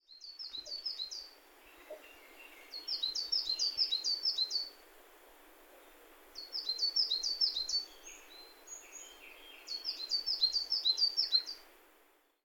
４　ヒガラ（日雀）　全長約11cm
カラ類の中でもっとも小型だが、大きな声で「ツピ、ツピ、ツピ・・・」と早口でさえずる。
【録音7】 　2025年5月5日　埼玉県県民の森